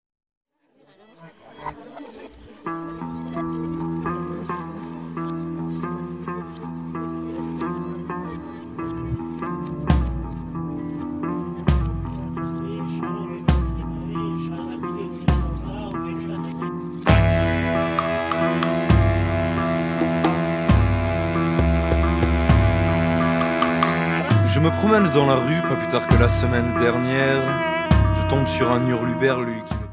Enregistré et mixé au studio Le Chalet fin 2004